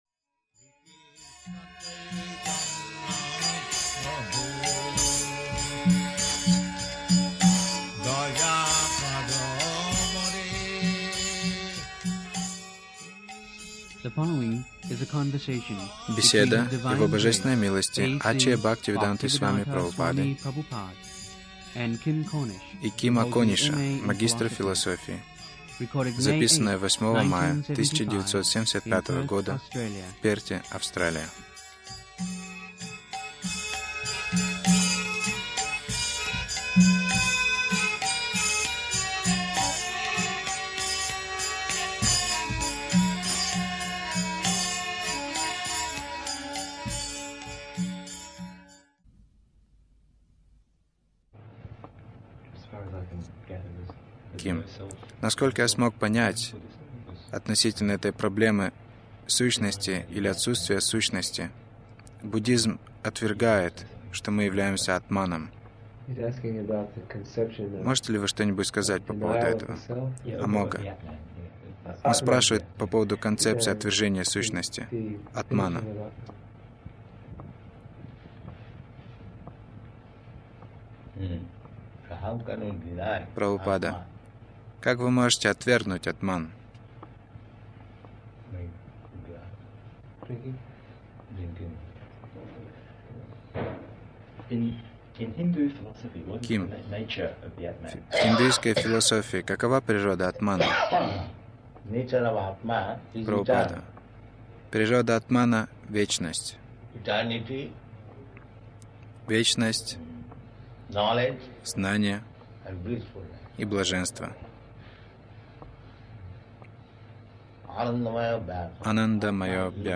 Беседа с магистром философии